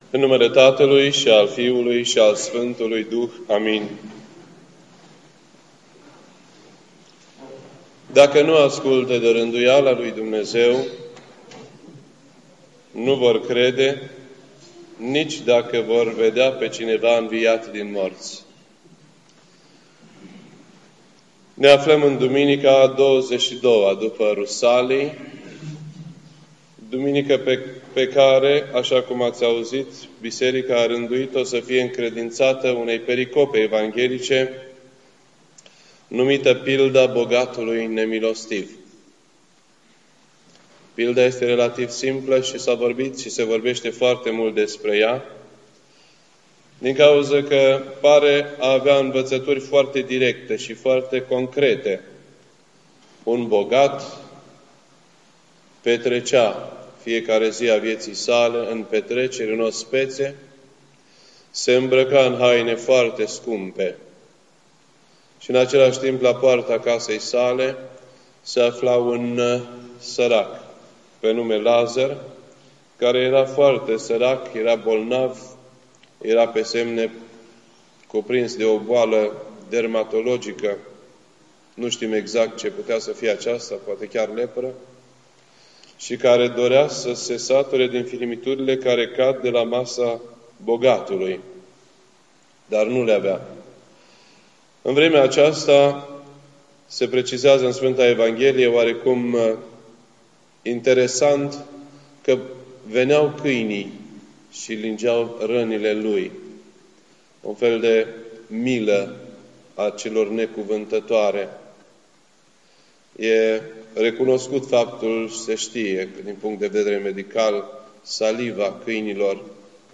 This entry was posted on Sunday, October 23rd, 2011 at 7:20 PM and is filed under Predici ortodoxe in format audio.